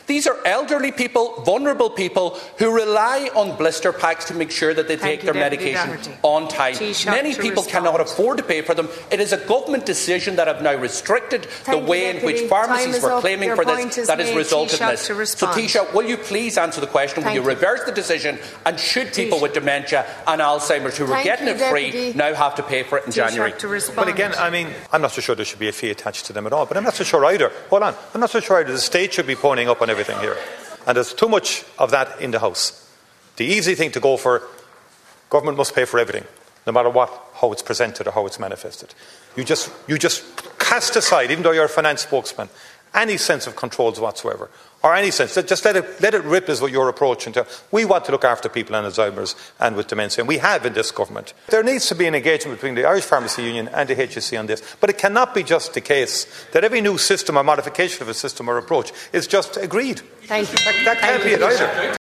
In the Dail today, Donegal TD and Sinn Fein Finance Spokesperson Pearse Doherty claimed these charges are happening because the government took its eye off the ball……………..